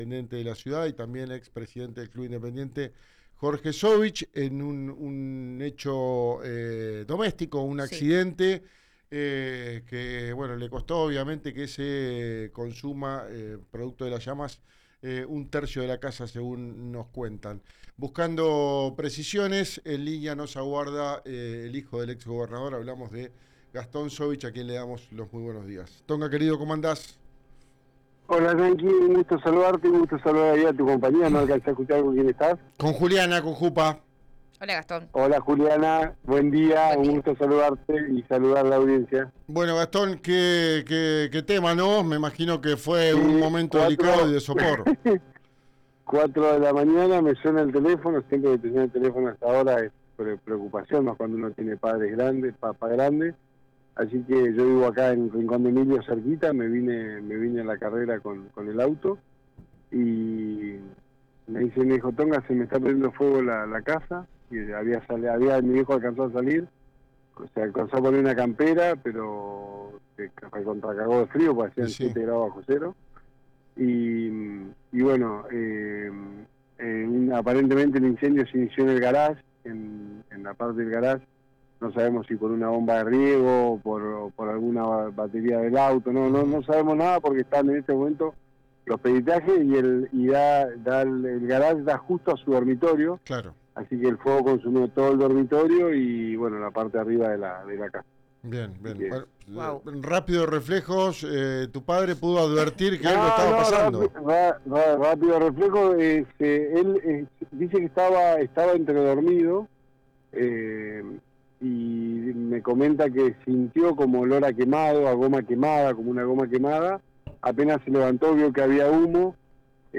En diálogo con RIO NEGRO RADIO dio detalles de lo ocurrido este martes por la mañana.